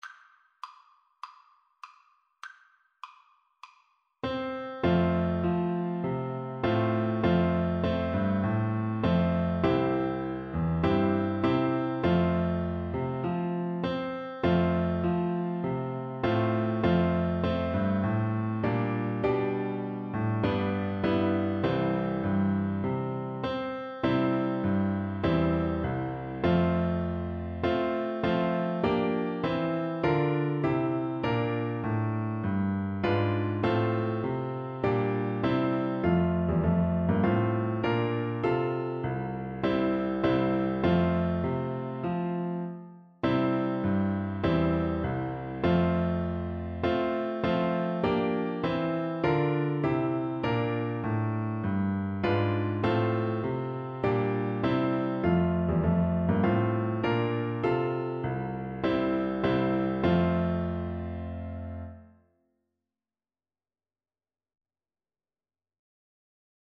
Flute version
A5-D7
Moderato